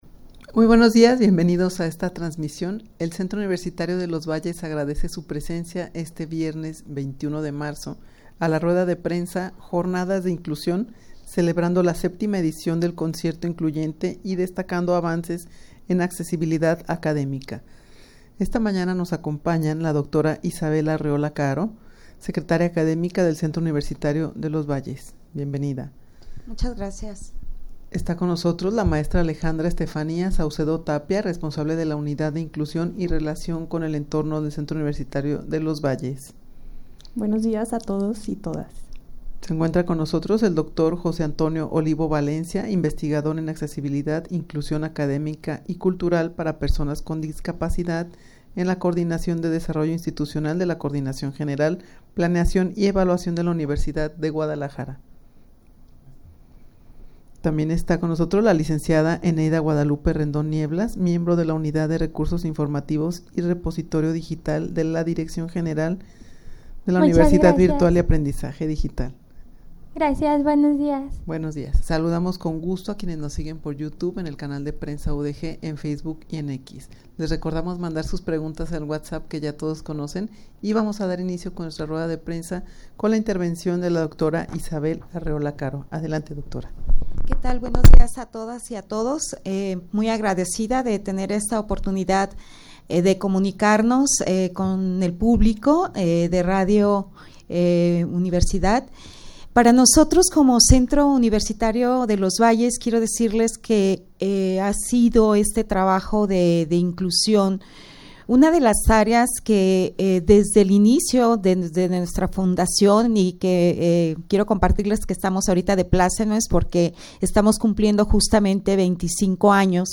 Audio de la Rueda de Prensa
rueda-de-prensa-jornadas-de-inclusion-de-cuvalles-celebrando-la-7a-edicion-del-concierto-incluyente-.mp3